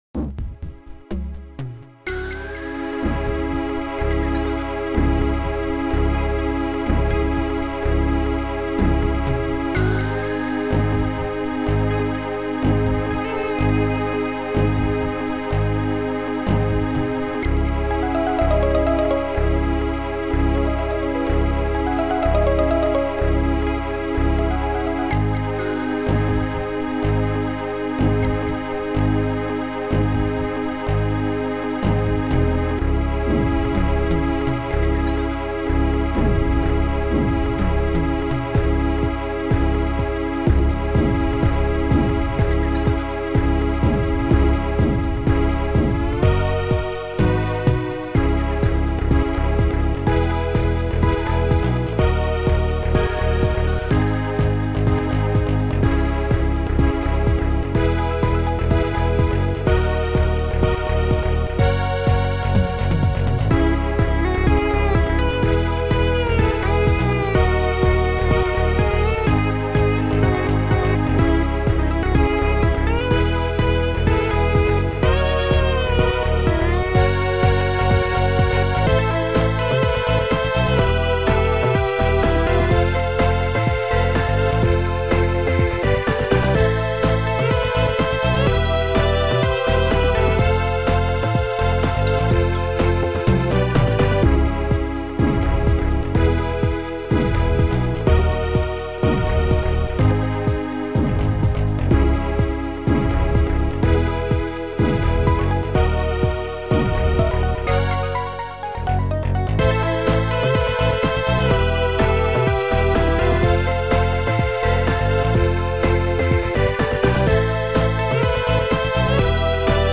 Noisetracker/Protracker
Synth Disco / Mellow